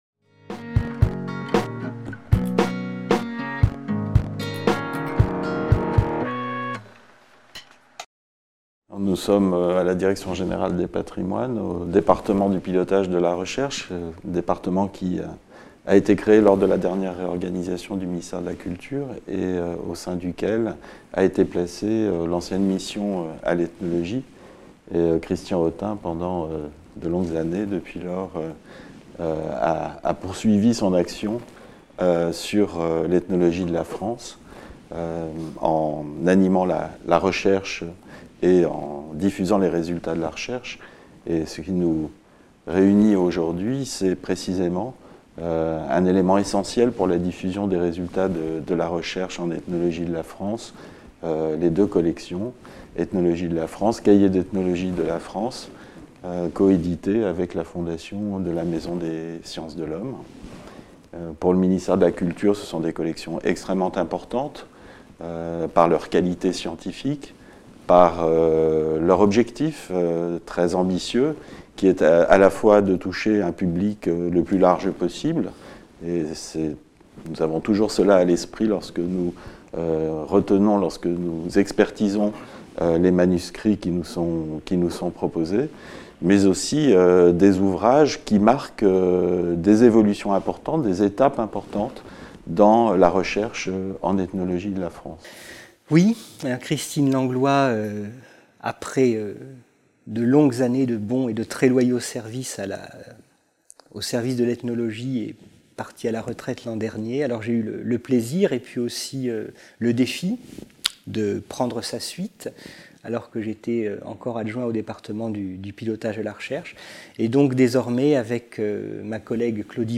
Journée "Ethnologie de la France", interview